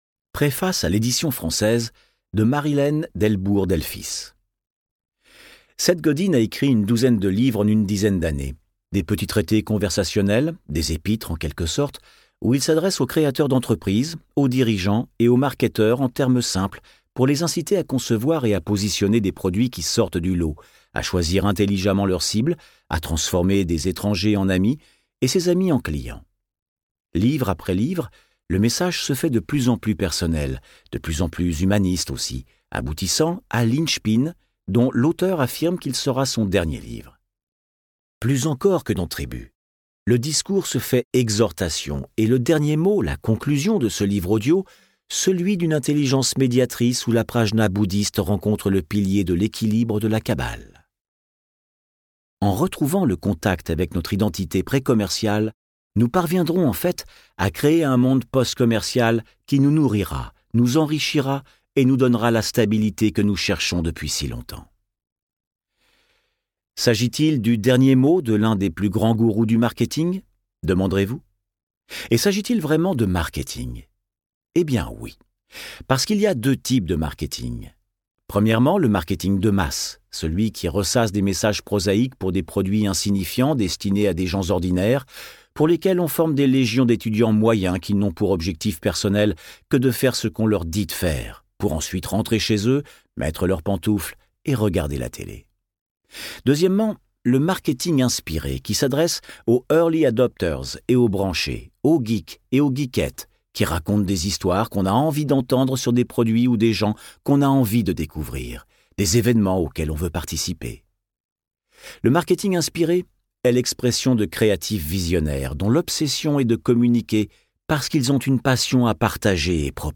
Extrait gratuit - Êtes-vous indispensable ? de Seth Godin, Seth Godin
Ce livre audio est différent.